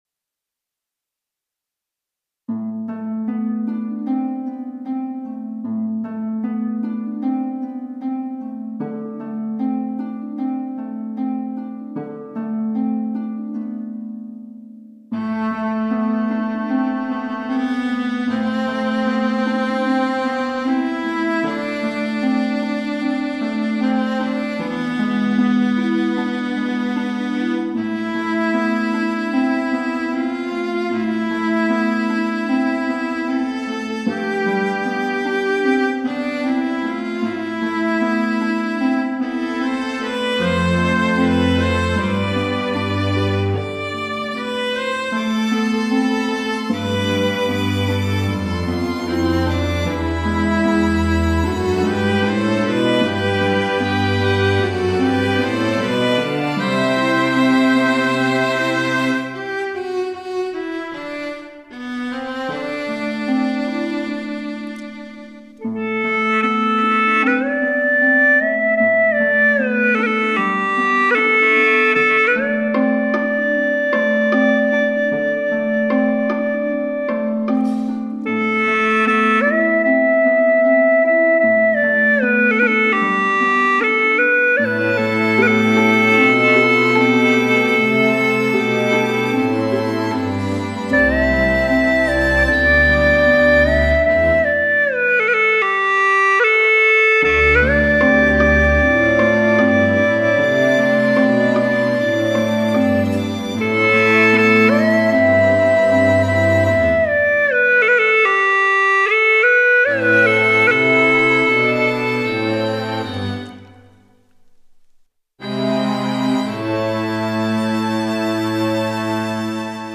调式 : G 曲类 : 独奏